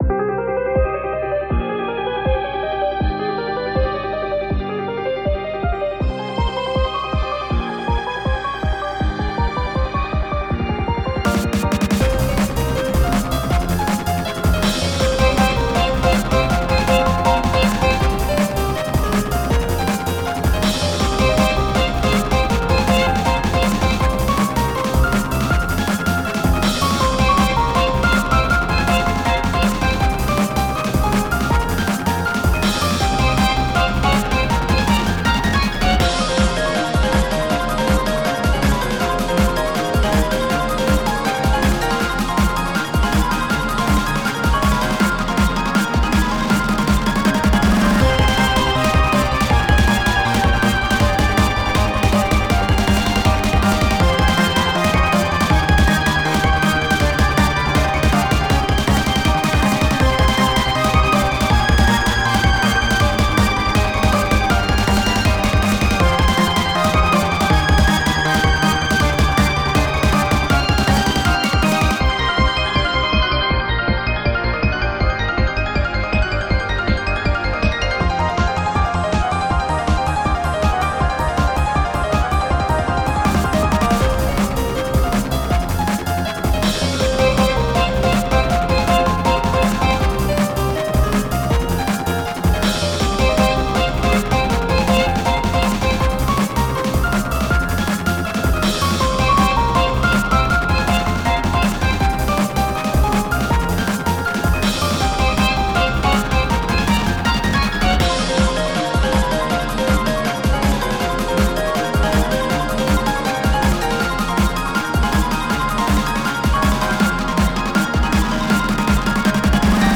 怪しげな雰囲気を出しつつ、綺麗でかっこいい曲を目指しました。